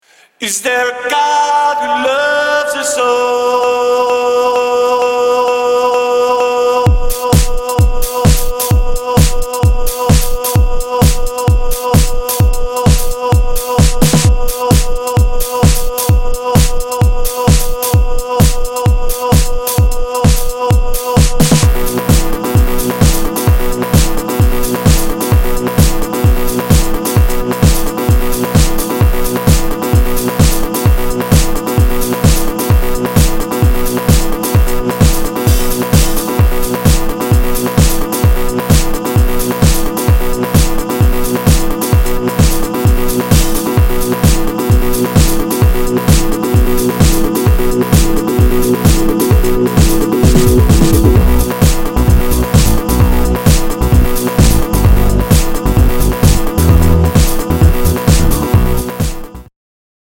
Dub